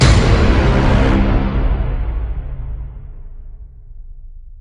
horn_2_dinDdY2.mp3